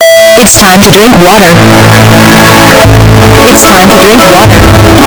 sip.ogg